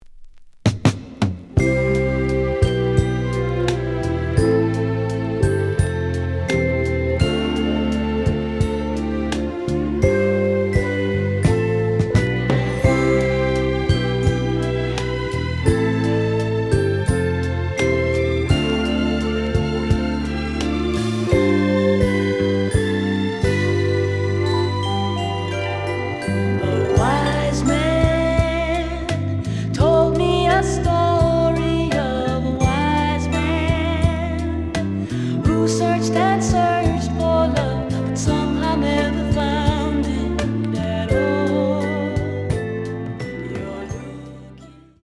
The audio sample is recorded from the actual item.
●Genre: Soul, 70's Soul
Some click noise on B side due to scratches.